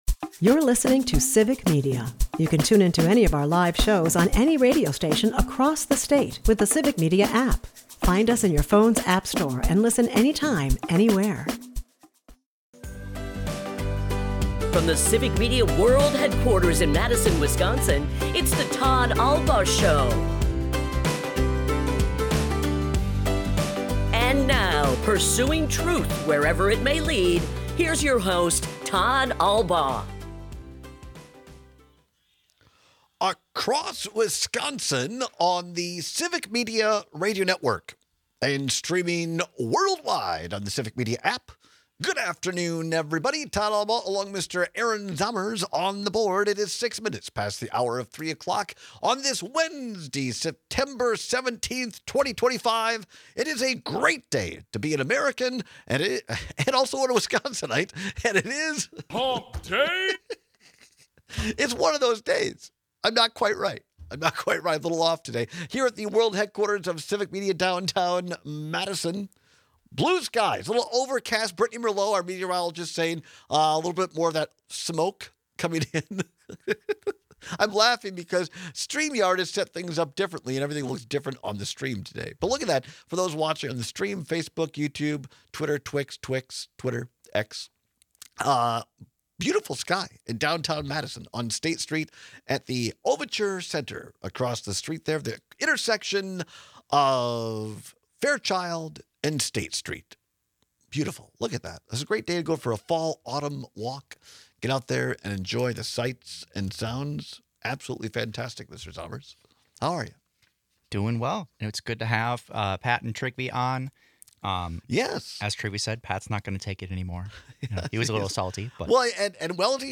We take your calls and texts over which one is easier to get rid of.